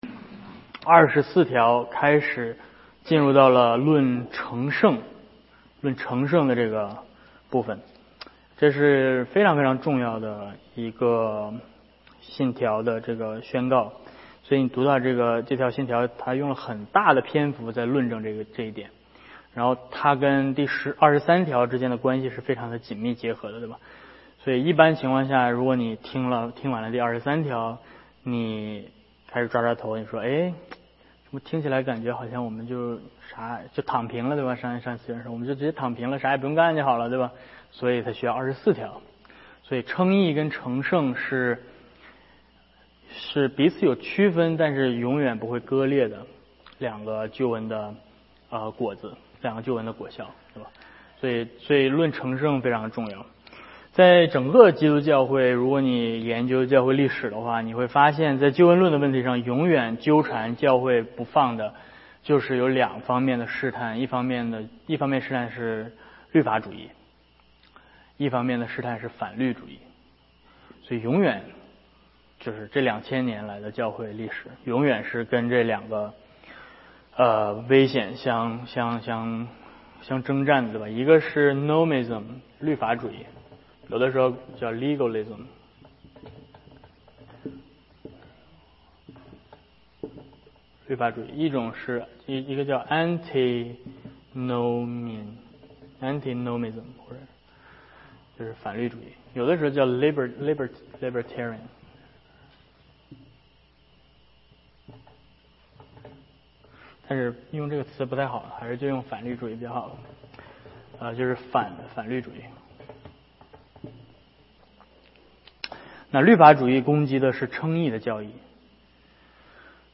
Service Type: 主日学课程